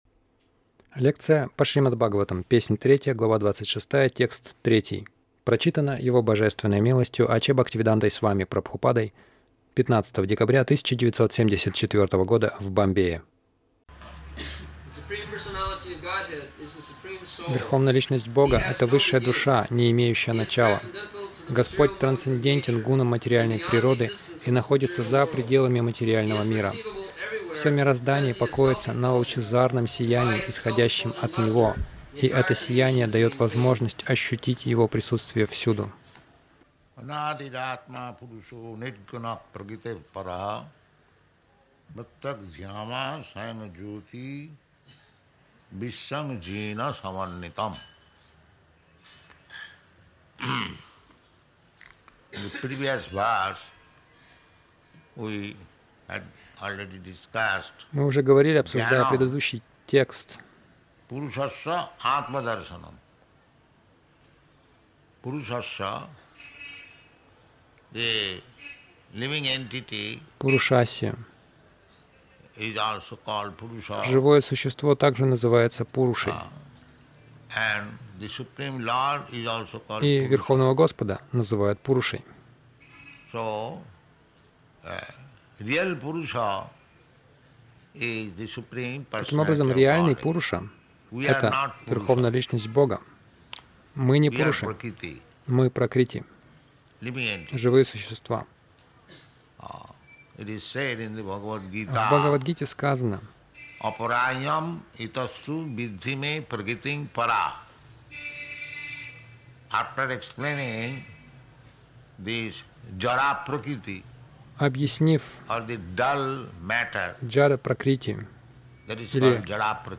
Милость Прабхупады Аудиолекции и книги 15.12.1974 Шримад Бхагаватам | Бомбей ШБ 03.26.03 — Бог присутствует повсюду Загрузка...